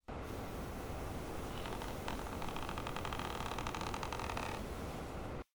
房间内部场景2.ogg